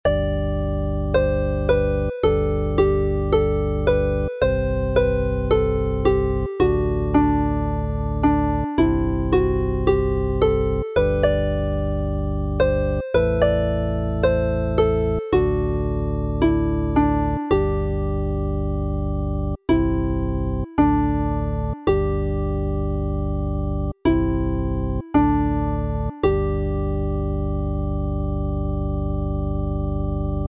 Alawon Cymreig - Set Meillionen - Welsh folk tunes to play
Play the melody n G